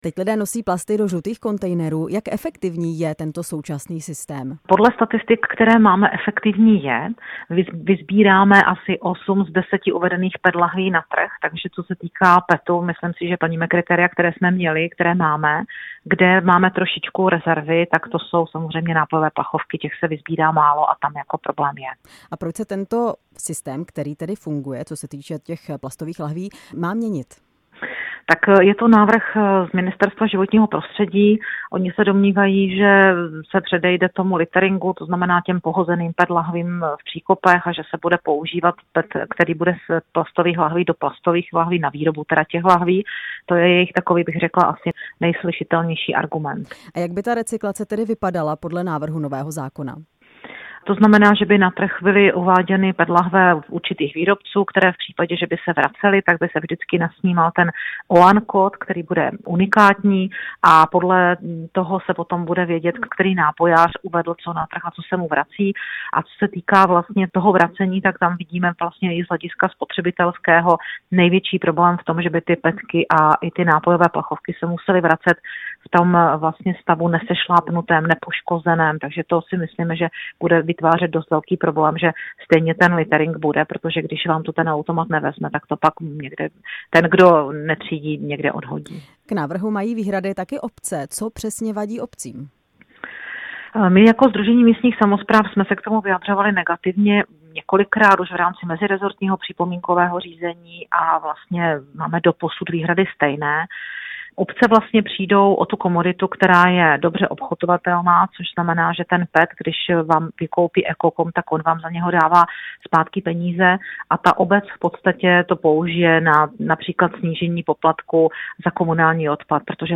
Na podrobnosti jsme se ptali předsedkyně Sdružení místních samospráv a poslankyně Elišky Olšákové z hnutí STAN, která zároveň působí jako radní ve Valašských Kloboukách.